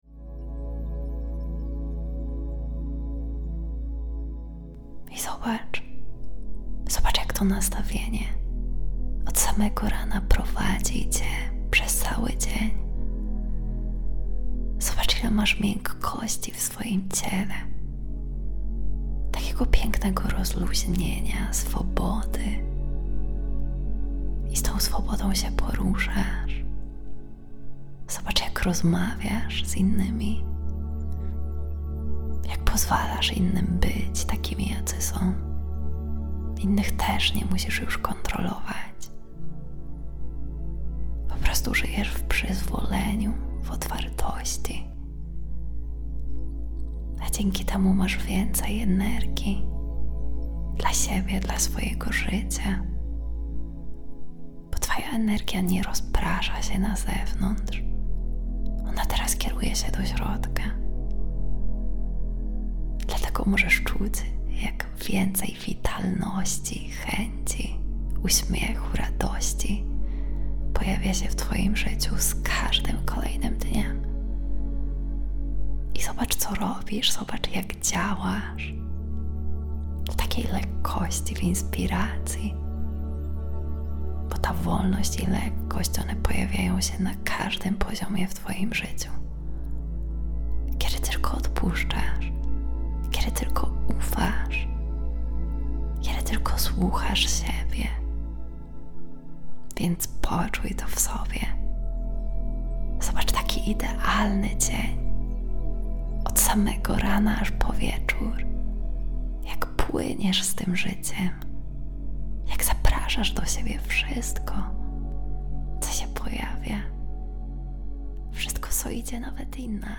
To głęboki proces terapeutyczny, który nagrałam tak, jakbym prowadziła Cię podczas indywidualnej sesji 1:1
Mój głos poprowadzi Cię w przestrzeń, w której możesz odpuścić presję, kontrolę i napięcie, a Twoje ciało i podświadomość nauczą się nowego stanu – zaufania, przepływu i lekkości.